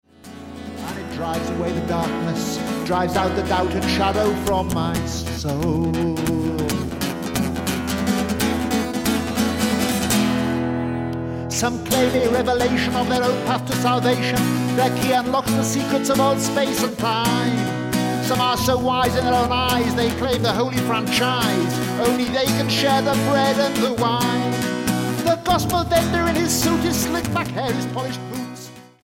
STYLE: Rock
clearly recorded on a budget